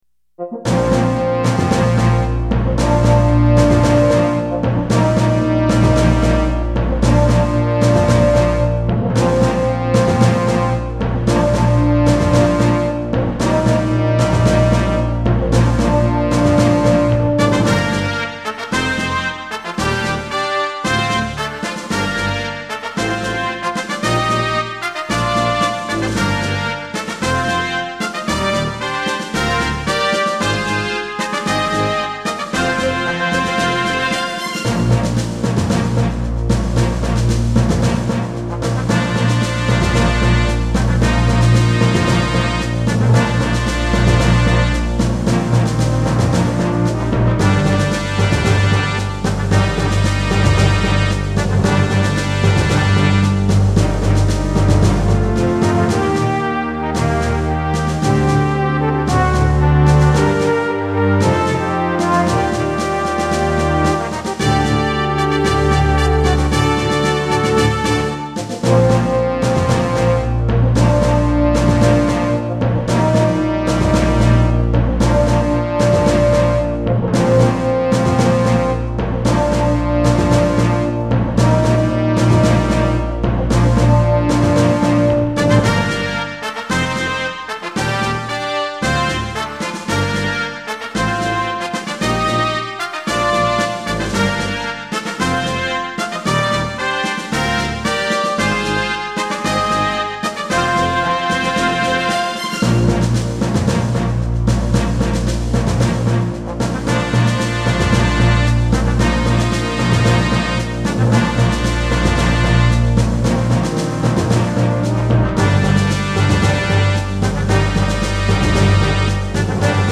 SC88Pro